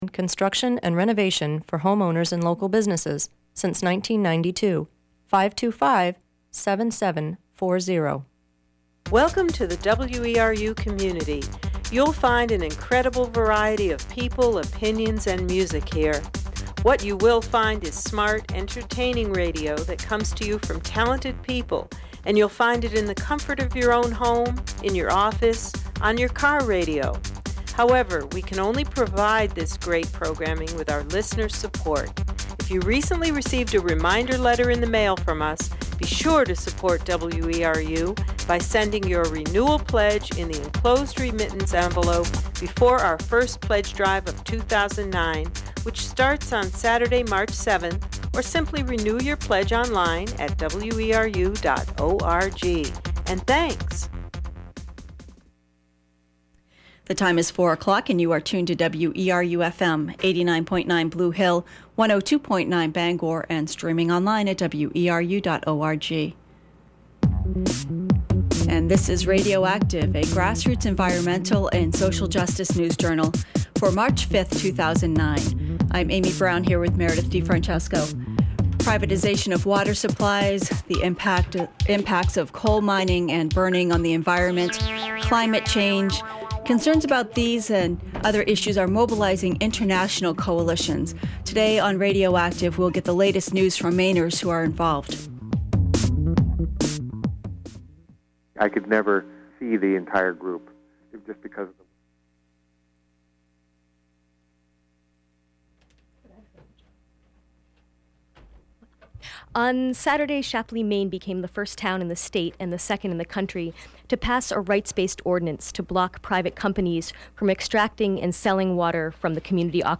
In Hancock County, the town of Lamoine’s conservation committee held an educational forum earlier this month.